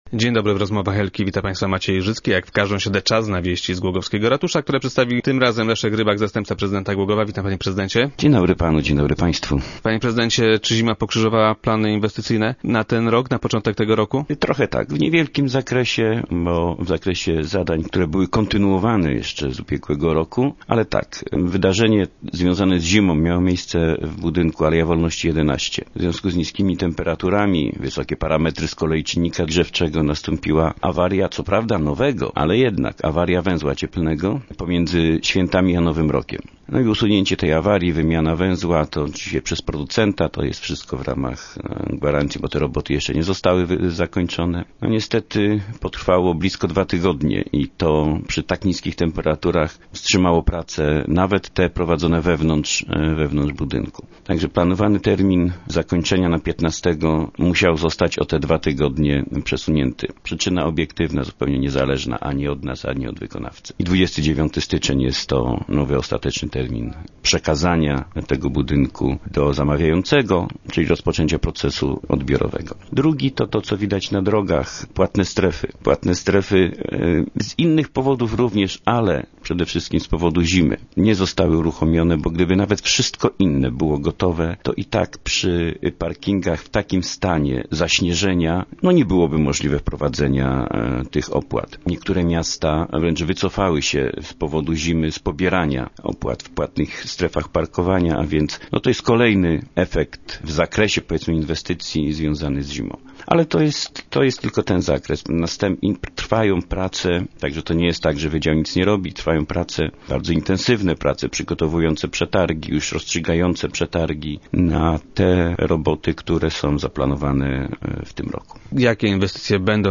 - Rozstrzygnięty został przetarg na realizację tej inwestycji - poinformował Leszek Rybak, zastępca prezydenta Głogowa, który był gościem Rozmów Elki.